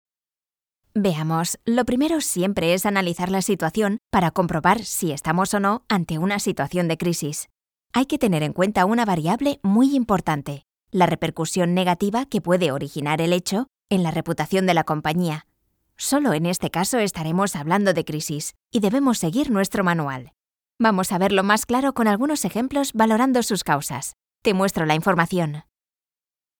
Young, Natural, Versatile, Warm, Soft
E-learning